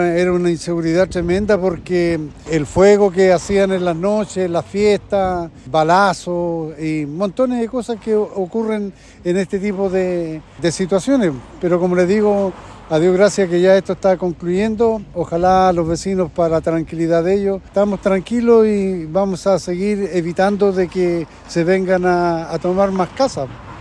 Vecinos del sector relataron a Radio Bío Bío que las viviendas estaban tomadas y eran usadas para la venta de droga. También reportaron fiestas y disparos durante las noches.
vecinos-toma-cuerpo-hualpen.mp3